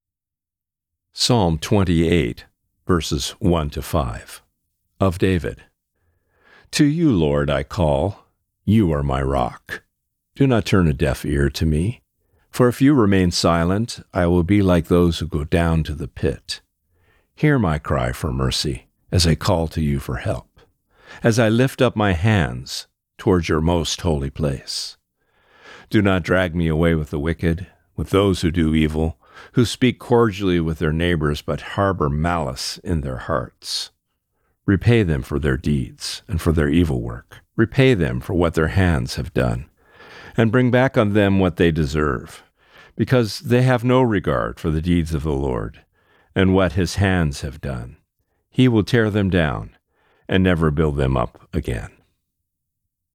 Reading: Psalm 28:1-5